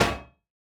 Minecraft Version Minecraft Version 25w18a Latest Release | Latest Snapshot 25w18a / assets / minecraft / sounds / block / heavy_core / break4.ogg Compare With Compare With Latest Release | Latest Snapshot
break4.ogg